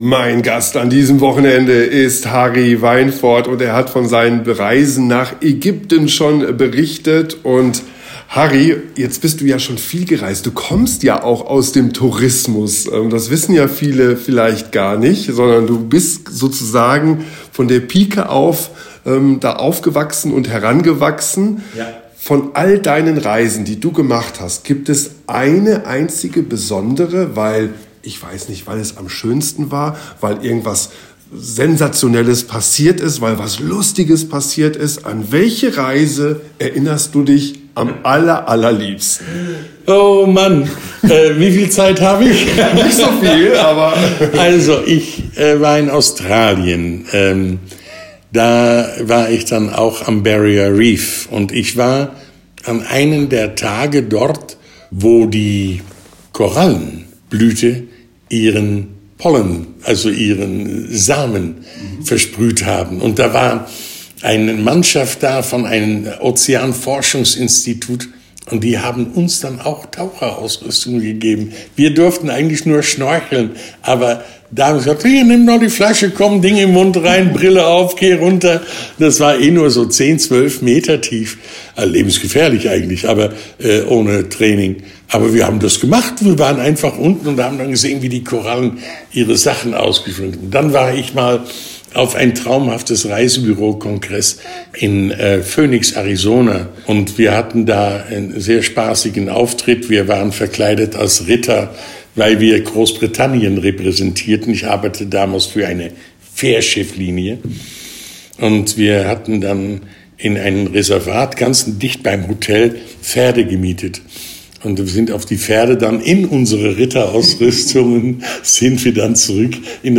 Harry Wijnvoord hat sich einmal mehr im Studio angesagt. Und das bedeutet wie immer tolle und unterhaltsame Geschichten aus den schönsten Urlaubsländern.